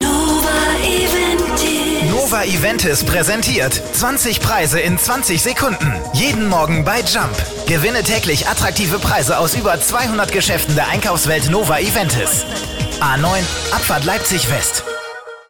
NOVA-EVENTIS (Funkspot)